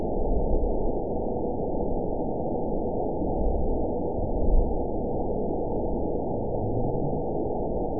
event 919720 date 01/19/24 time 08:51:07 GMT (1 year, 10 months ago) score 8.99 location TSS-AB04 detected by nrw target species NRW annotations +NRW Spectrogram: Frequency (kHz) vs. Time (s) audio not available .wav